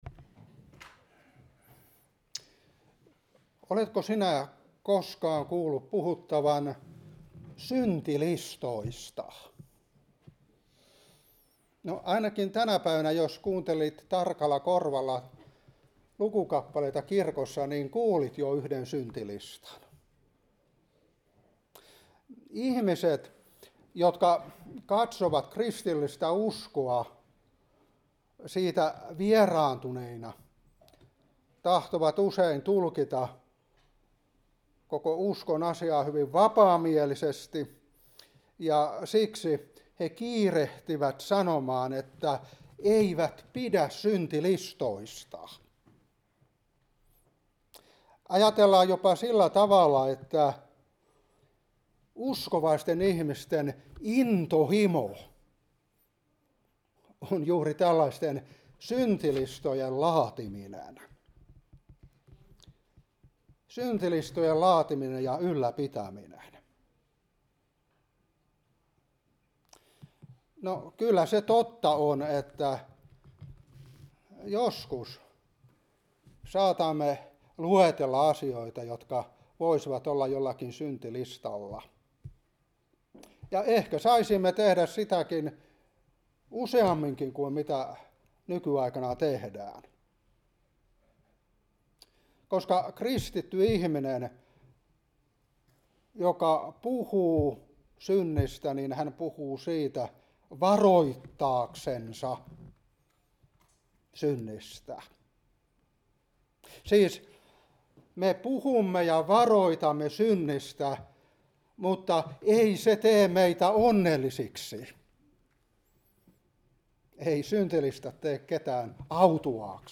Seurapuhe 2026-3